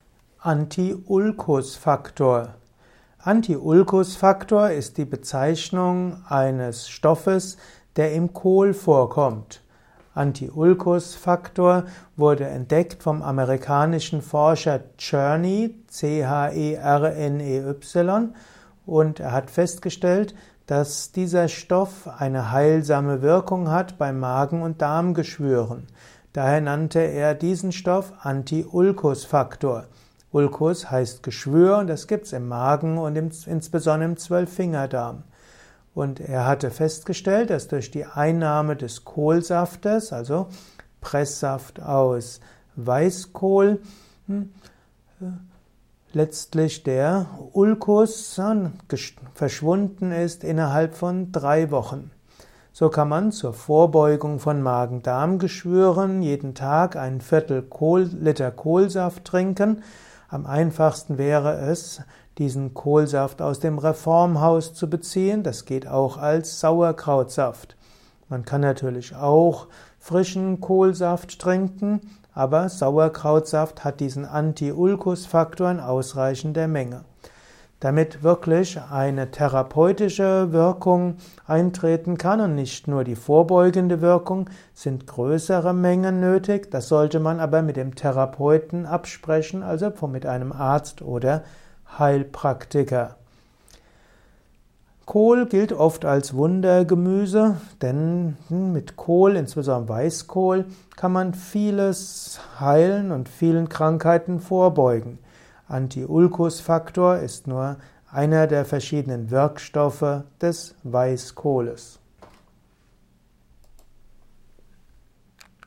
Informationen zum Anti-Ulkus-Faktor in diesem Kurzvortrag